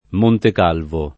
montek#lvo] top. e cogn. — tre comuni: Montecalvo Irpino [montek#lvo irp&no] (Camp.); Montecalvo in Foglia [montek#lvo in f0l’l’a] (Marche); Montecalvo Versiggia [montek#lvo verS&JJa] (Lomb.)